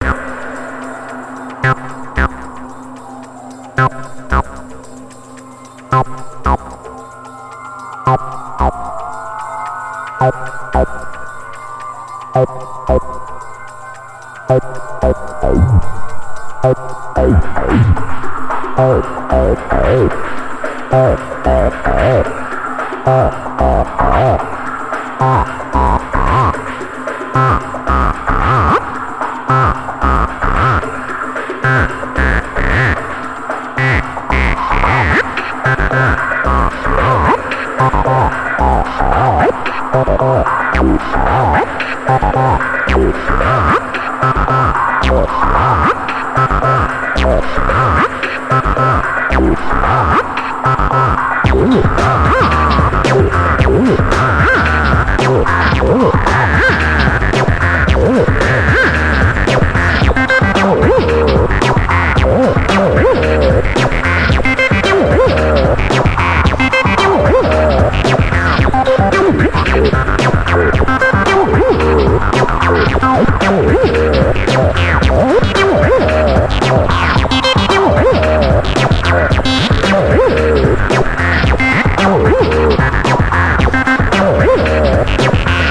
Bassline Fun
In the background FM modulated oscillators through a reverb effect.
[2] external effects have been added
[3] StylusRMX based drums have been added